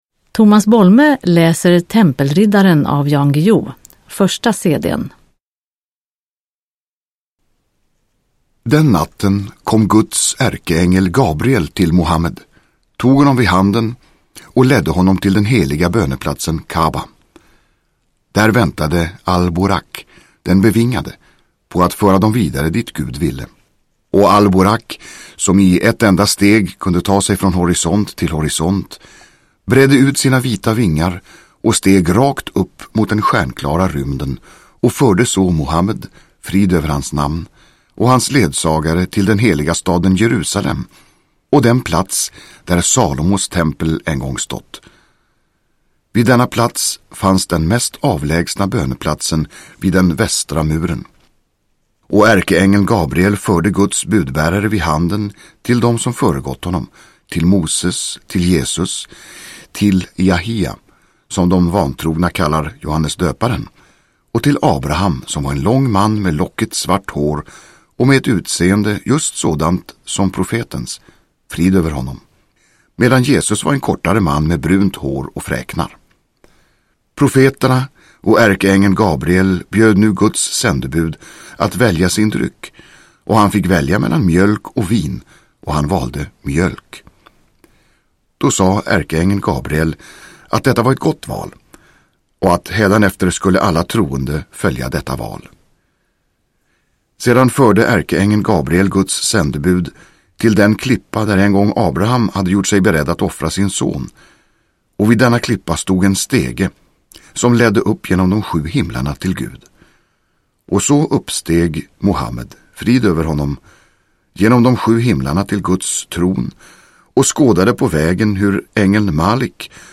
Tempelriddaren / Ljudbok